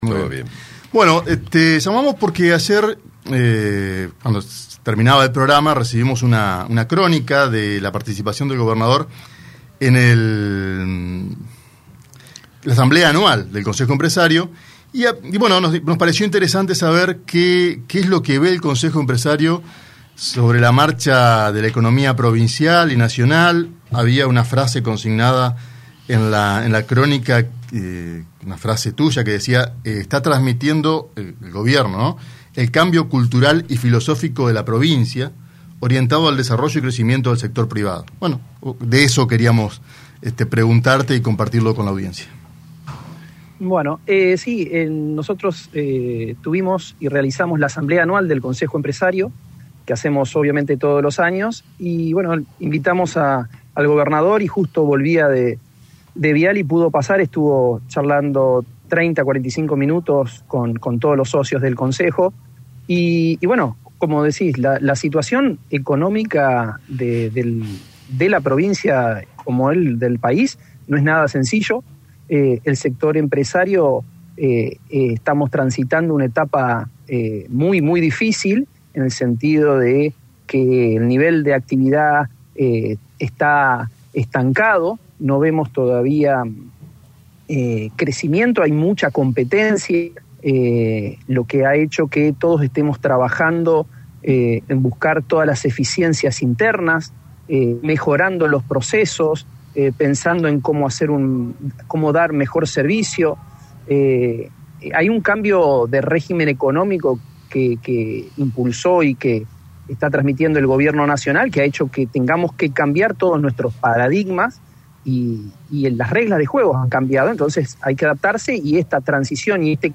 una entrevista con el programa Buen Día